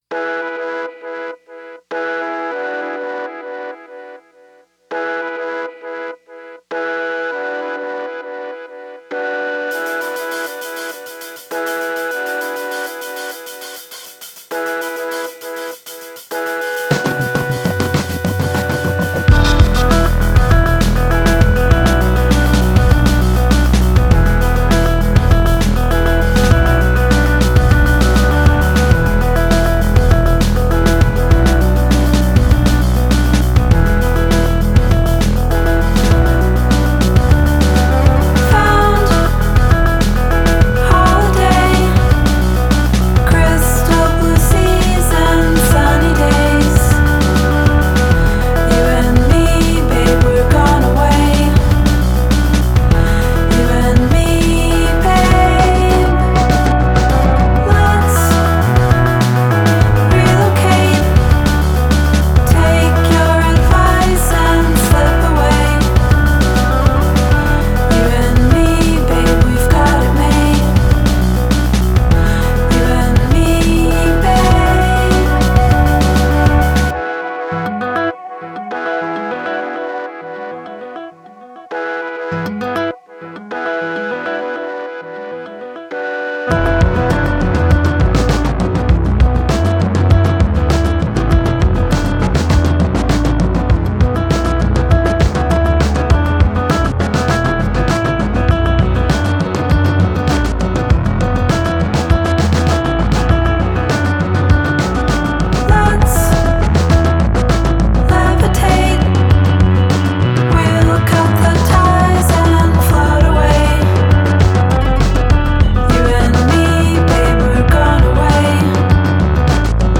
Genre: Indie Pop-Rock / Indie-Folk /